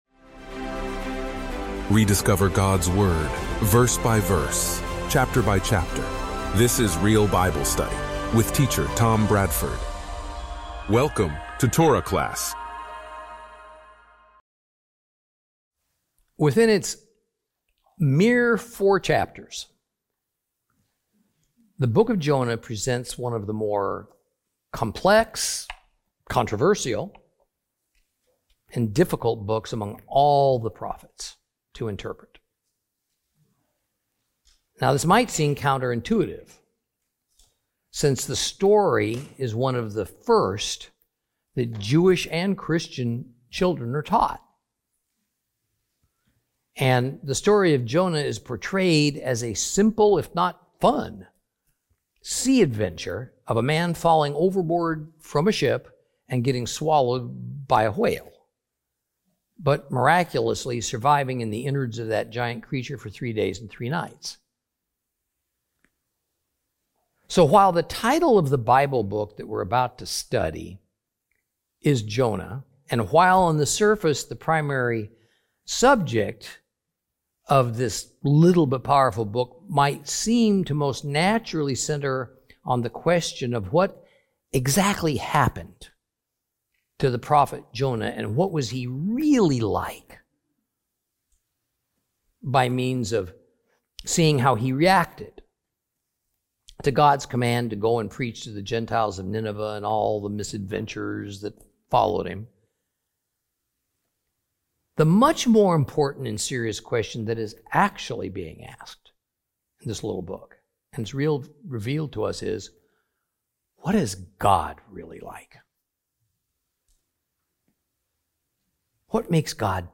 Teaching from the book of Jonah, Lesson 1 Introduction.